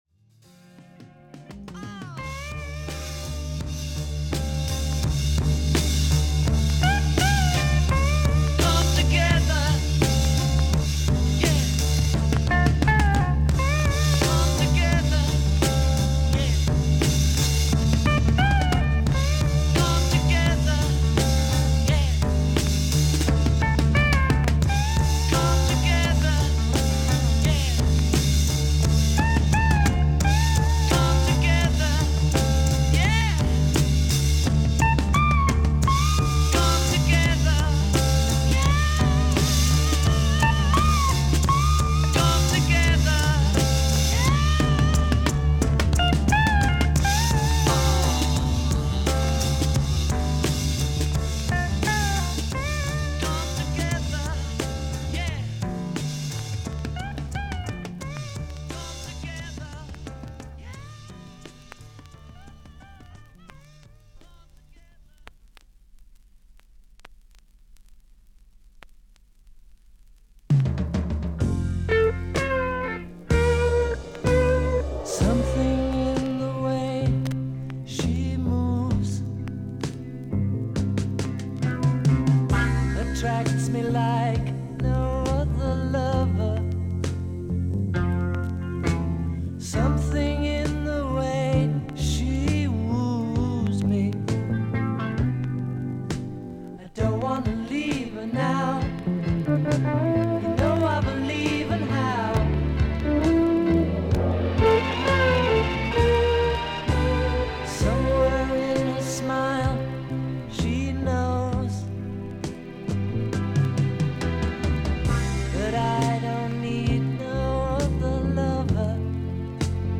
A1終盤からA2序盤まで7mmのキズ、A2序盤に少々キズがあり周回ノイズがあります。
音のグレードはVG+〜VG++:少々軽いパチノイズの箇所あり。少々サーフィス・ノイズあり。クリアな音です。